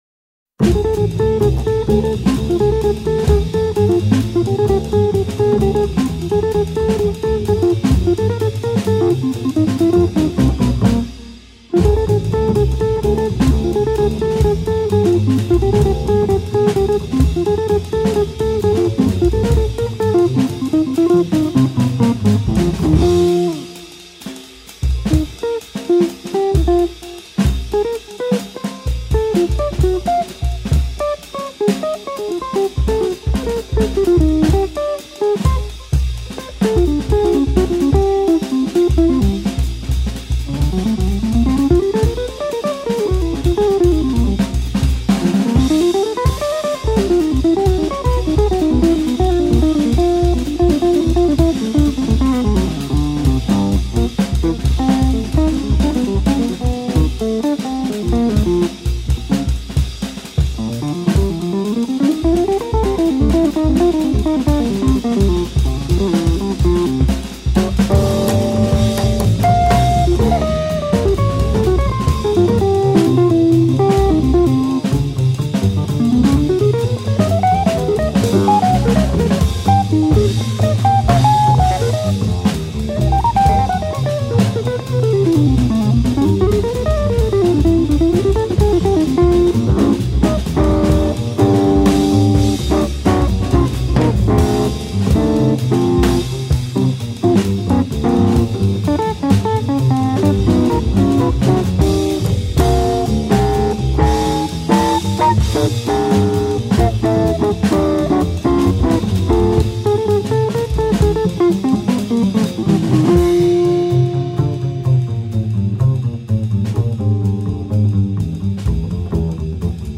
guitar
double bass
drums